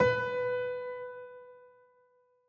🌲 / AfterStory Doki Doki Literature Club game mod_assets sounds piano_keys
B4.ogg